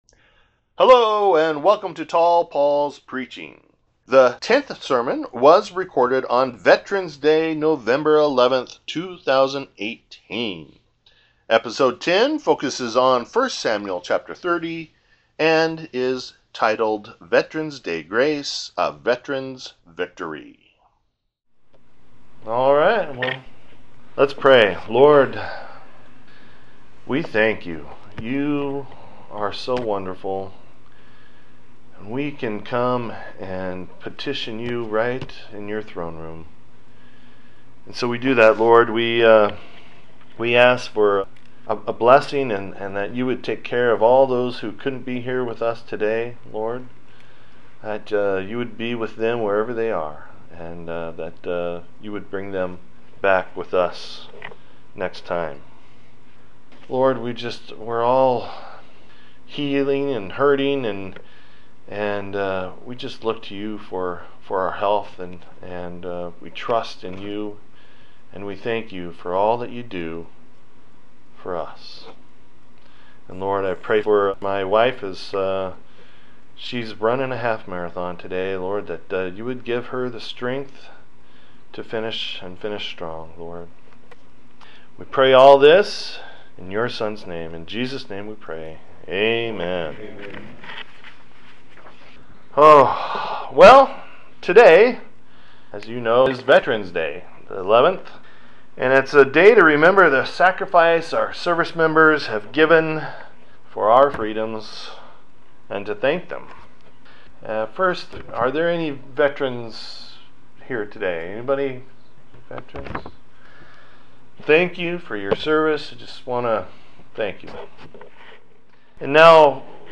This 10th sermon focuses on thanking God and our veterans.